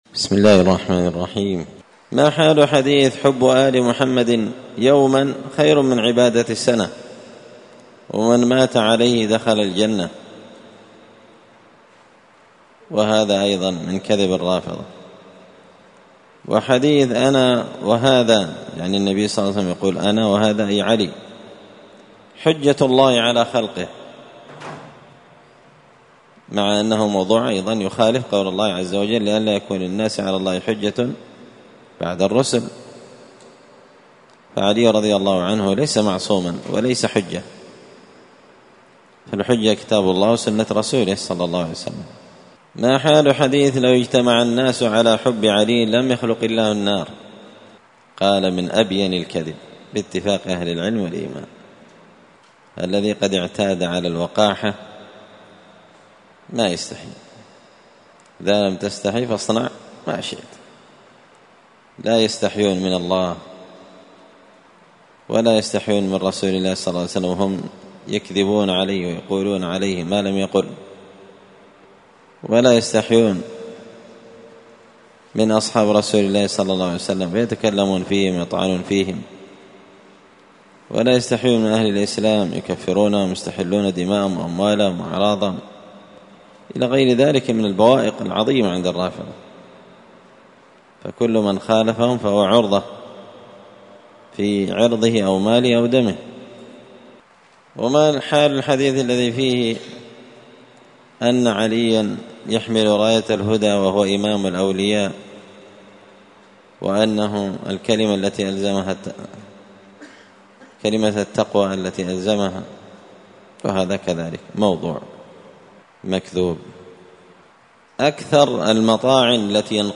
الأربعاء 25 ذو القعدة 1444 هــــ | الدروس، دروس الردود، مختصر منهاج السنة النبوية لشيخ الإسلام ابن تيمية | شارك بتعليقك | 10 المشاهدات